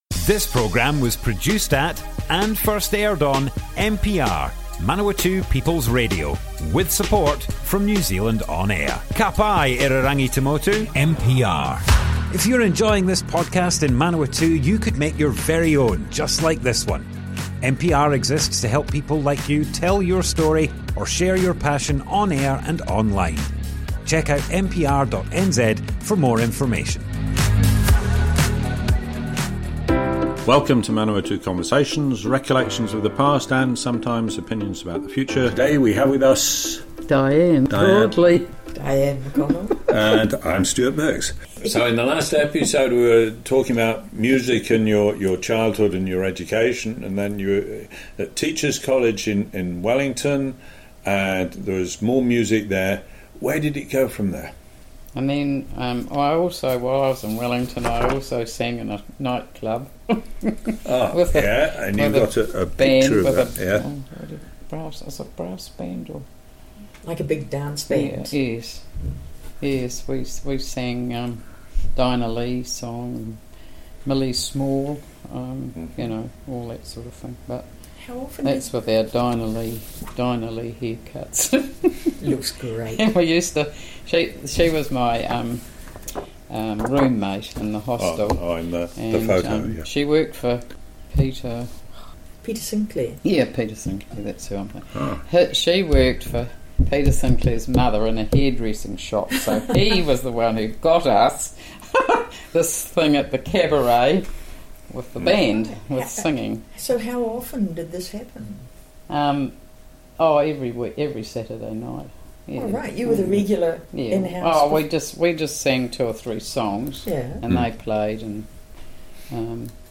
Manawatu Conversations More Info → Description Broadcast on Manawatu People's Radio, 14th October 2025.
oral history